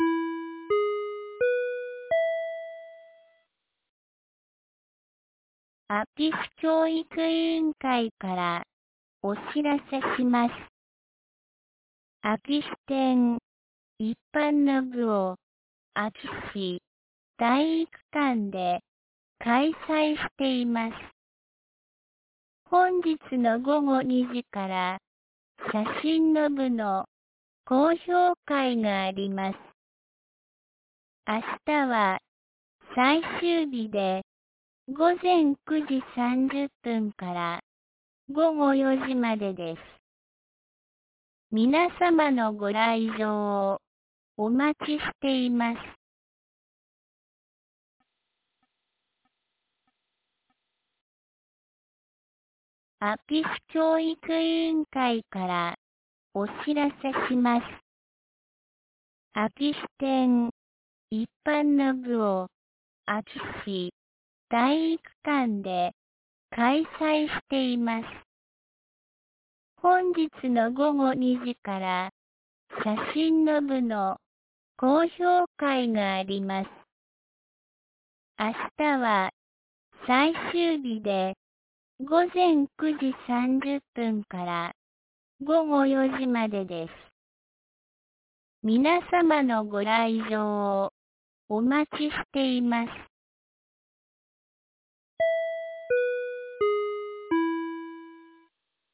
2023年11月11日 12時11分に、安芸市より全地区へ放送がありました。